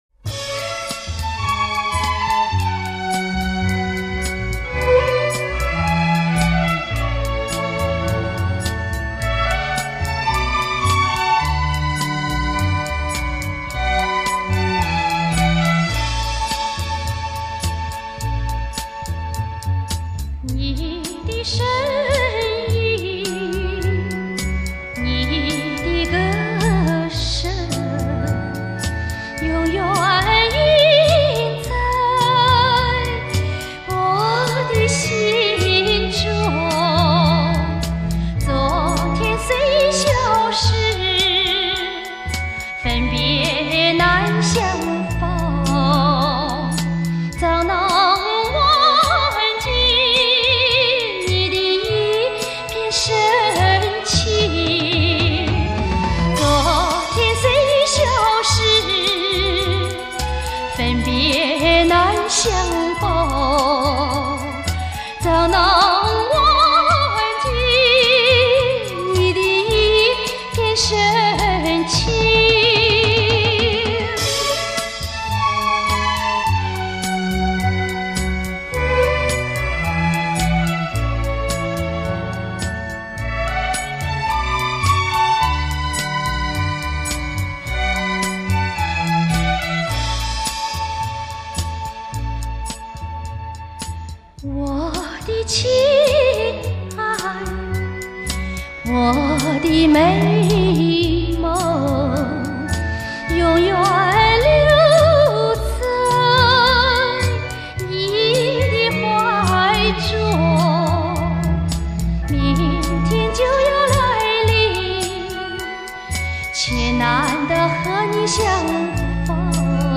首首经典，倾情演唱。